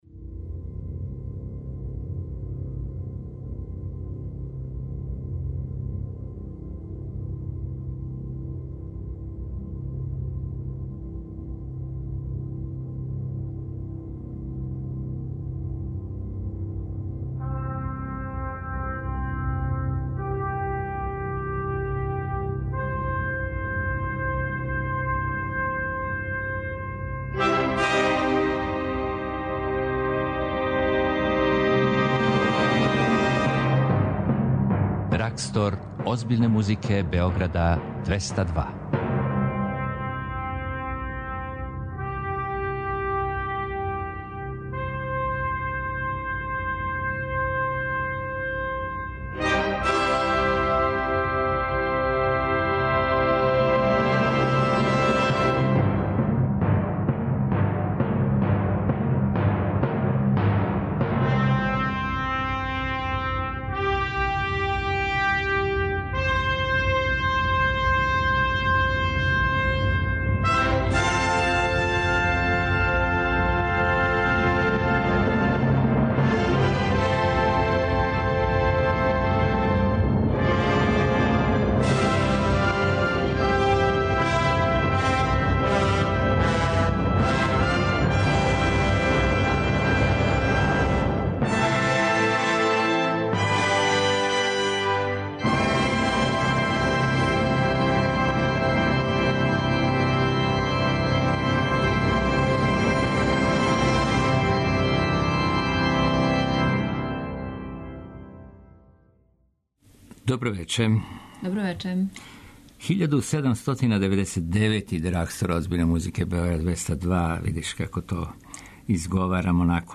У вечерашњем Драгстору подсетићемо се чувених дела класичне музике попут Листове Мађарске рапсодије бр. 2, Бетовенове Оде Радости и Вердијевог Хора Јевреја.
Симфонија звучи у џез аранжману и емитовати композиције које се ређе могу чути – попут Клавирског концерта Франсоа Боладјуа!
слушамо концерт пијанисткиње